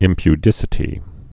(ĭmpy-dĭsĭ-tē)